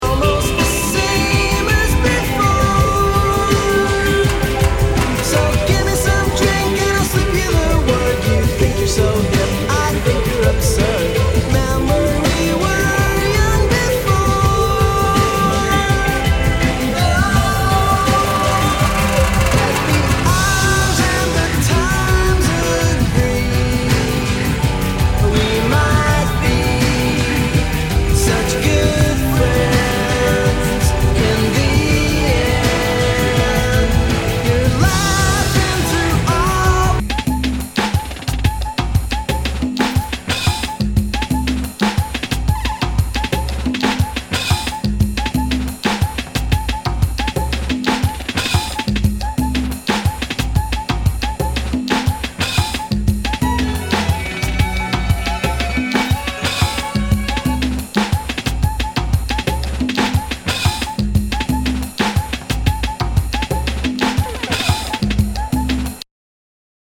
[ Genre ] Nu- Jazz/BREAK BEATS
ナイス！ダウンテンポ / インディー・ロック！！